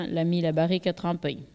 Localisation Sallertaine
collecte de locutions vernaculaires
Catégorie Locution